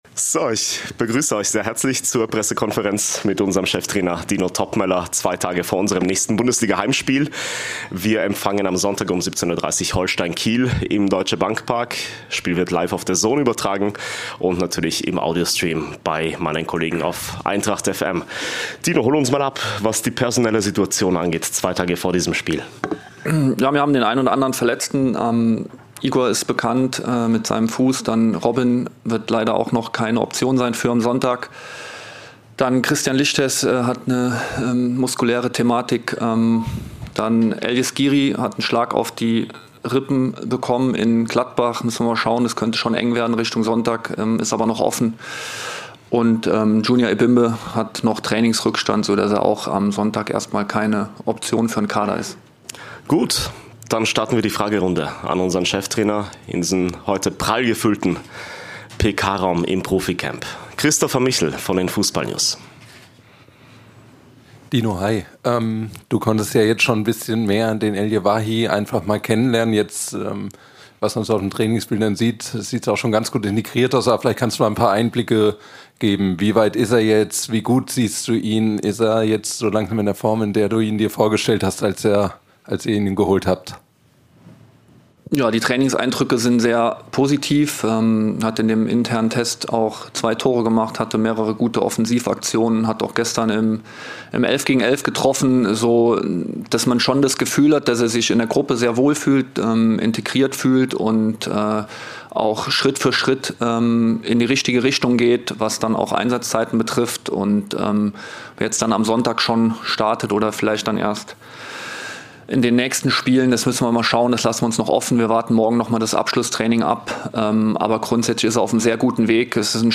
Die Pressekonferenz mit unserem Cheftrainer Dino Toppmöller vor dem Bundesliga-Heimspiel gegen Aufsteiger Holstein Kiel.